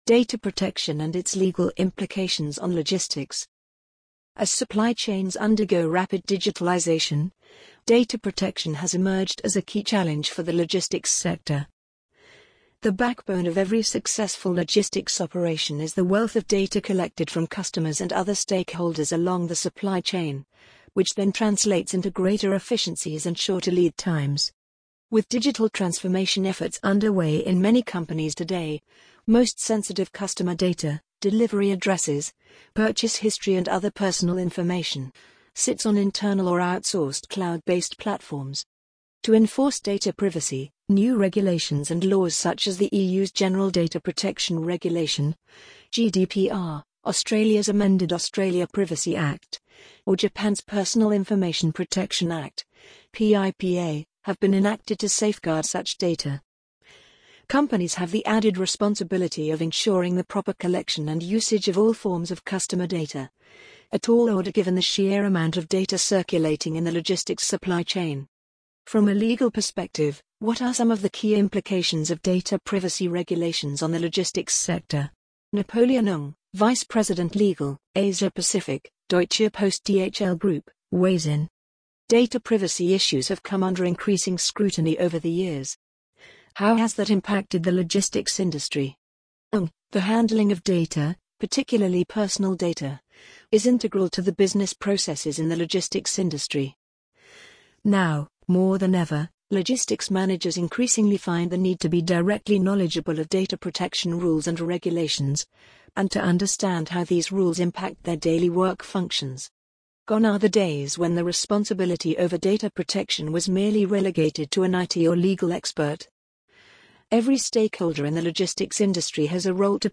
amazon_polly_3086.mp3